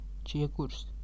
Ääntäminen
IPA : /kəʊn/